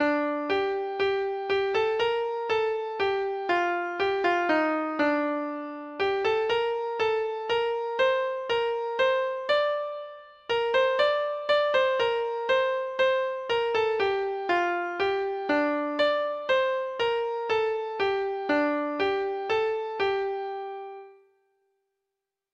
Free Sheet music for Treble Clef Instrument
Traditional Music of unknown author.